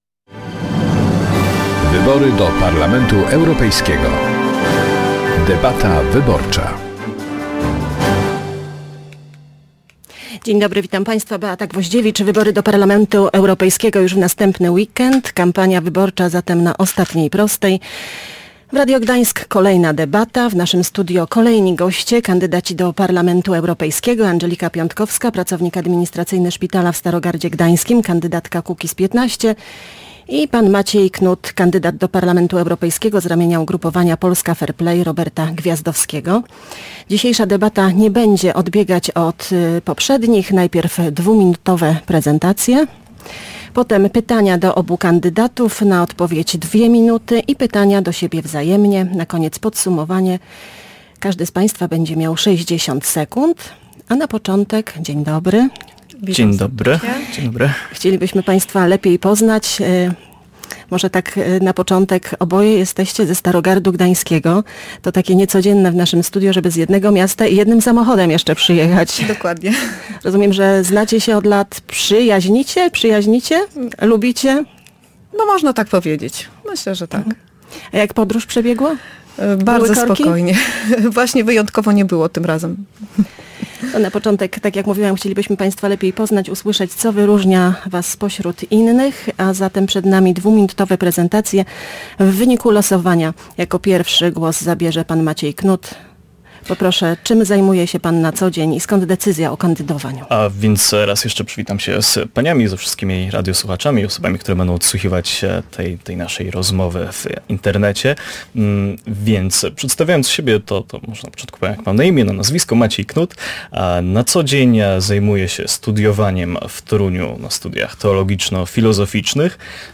W Radiu Gdańsk odbyła się kolejna debata przedwyborcza.